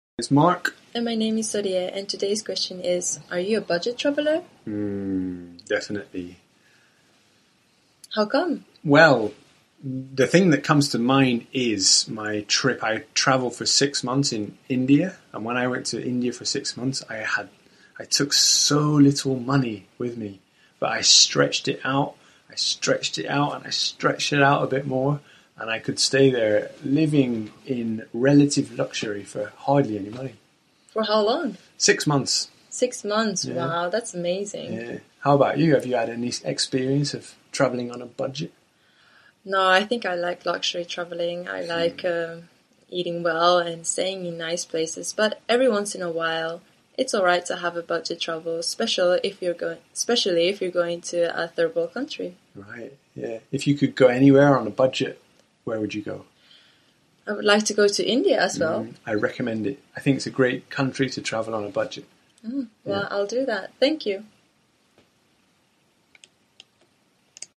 实战口语情景对话 第1325期:Are you a budget traveller? 你是经济型旅行者吗?